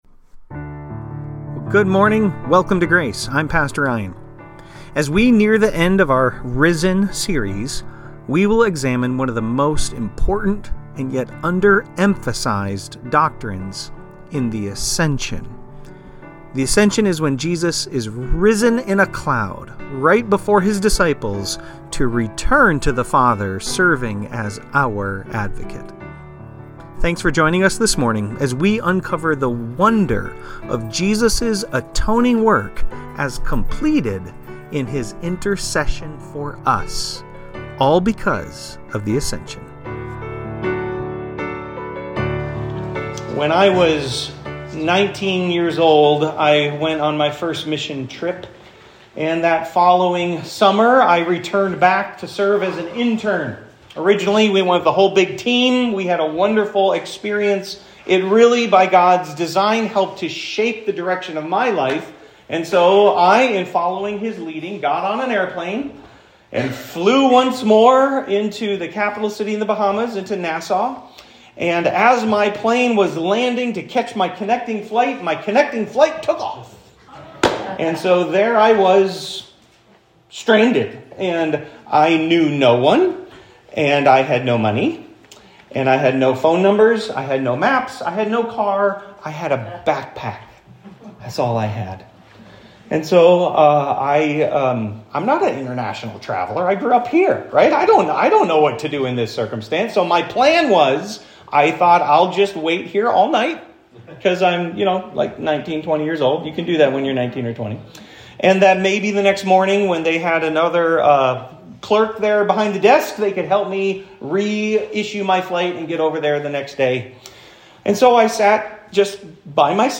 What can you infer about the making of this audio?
Raised – The Ascension – Grace Church